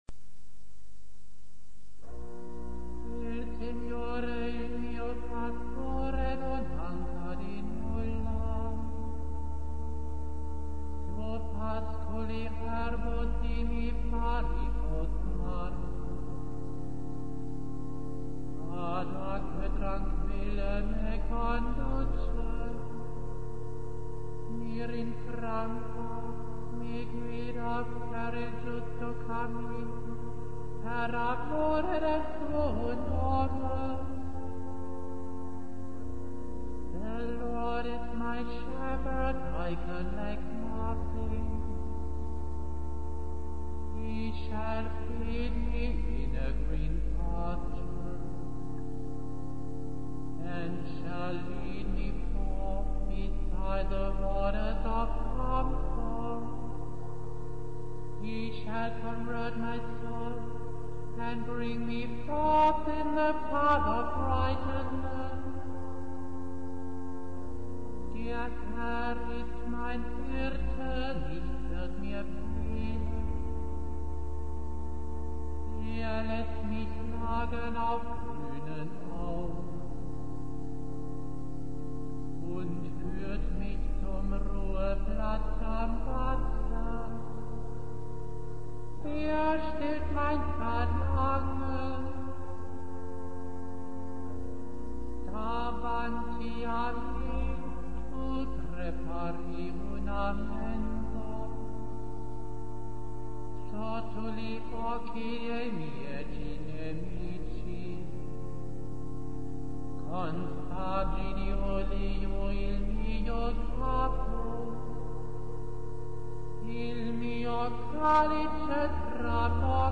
internationale Gesänge zur Eucharistiefeier
mit Kehrversen und Solo- bzw. Chorstrophen